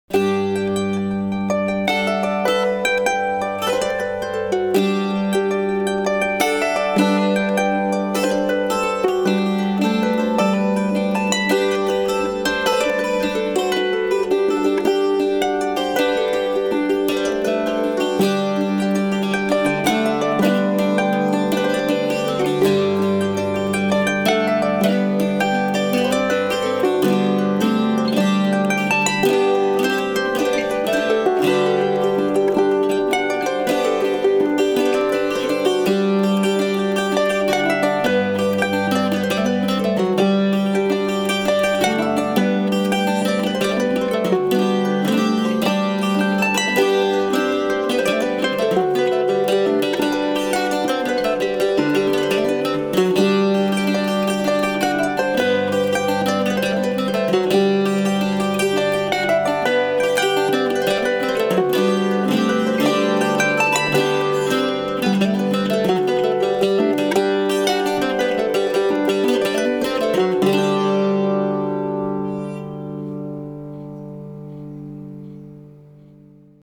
Celtic harp,Irish fiddle, whistle, Uilleann pipes and other instruments.
Live Celtic Wedding Music - Celtic Harp, Irish whistle, flute, uilleann pipes, bouzouki.
The samples were recorded as straight takes without multitracking, so they give an accurate impression of how we sound live.